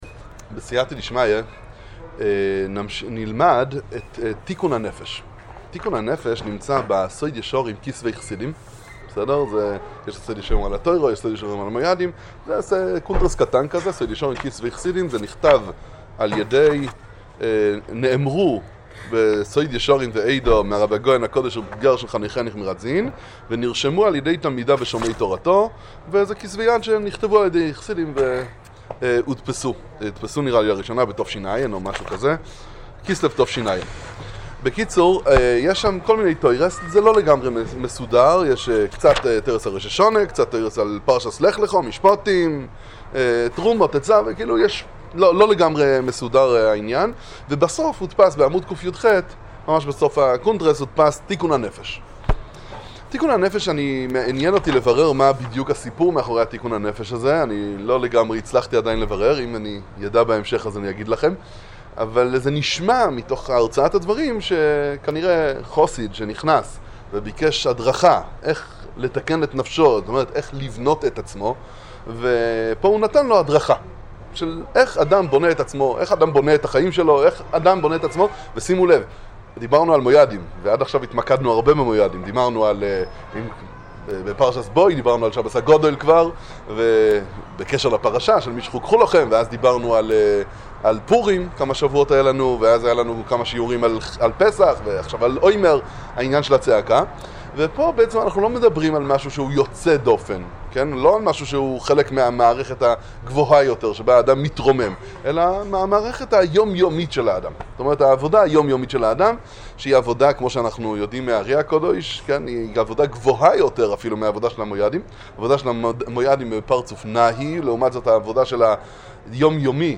שיעור בספרי איז'ביצא ראדזין.